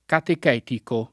vai all'elenco alfabetico delle voci ingrandisci il carattere 100% rimpicciolisci il carattere stampa invia tramite posta elettronica codividi su Facebook catechetico [ katek $ tiko ] agg.; pl. m. -ci — cfr. catechistico